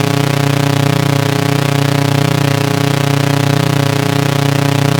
engineCircular_002.ogg